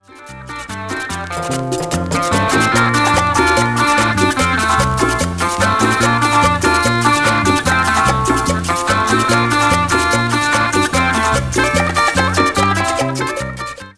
thinly distorted guitar work